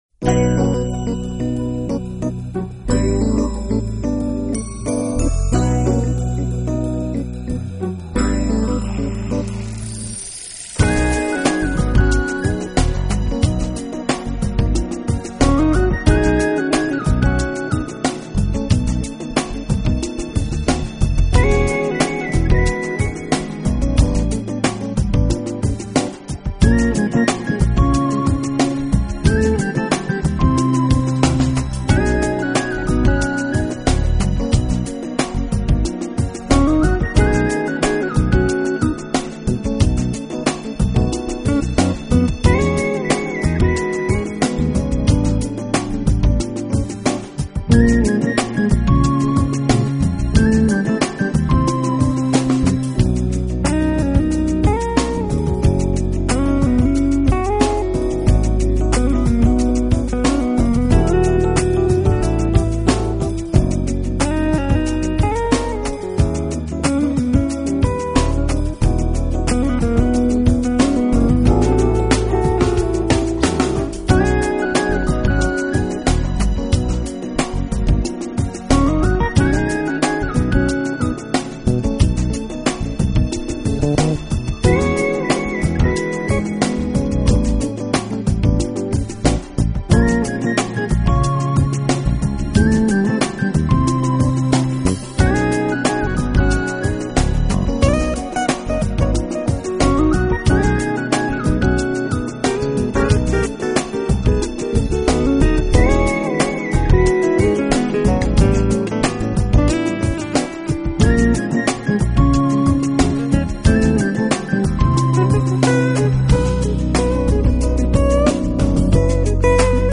音乐风格: Jazz